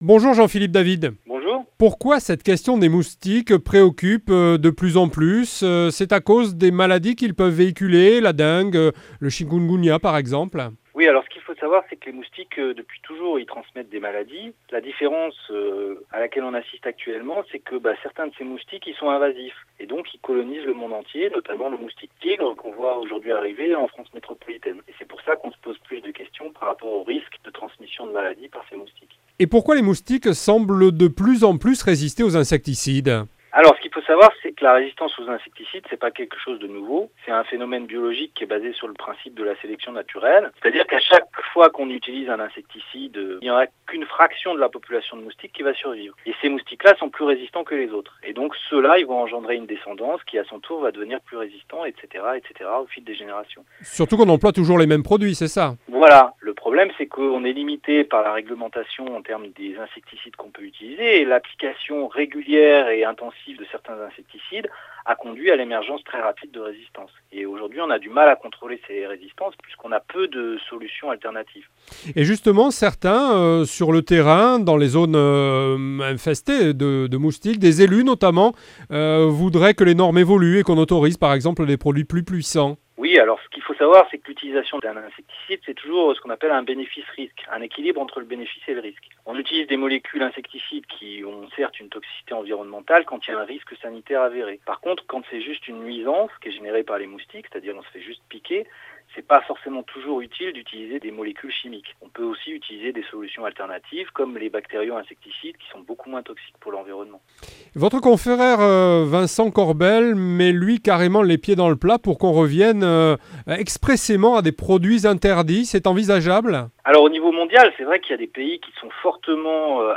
French Radio